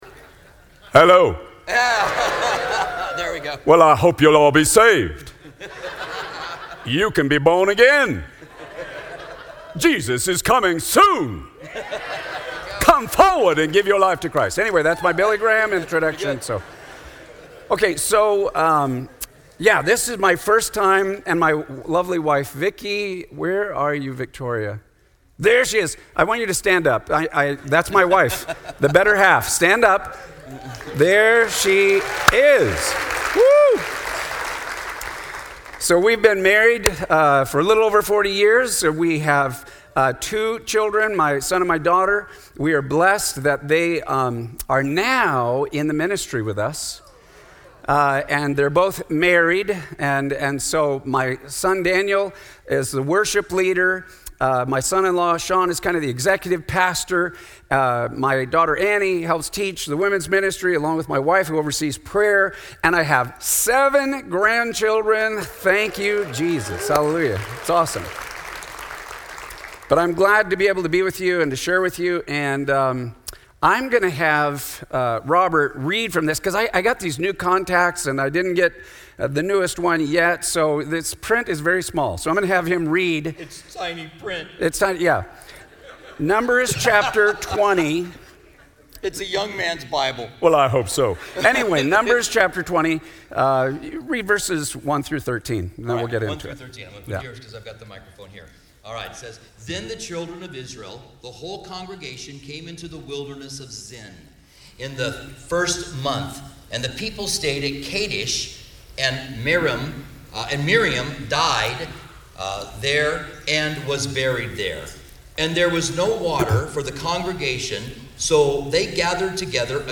Southwest Pastors and Leaders Conference 2020